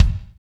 KIK F S K01R.wav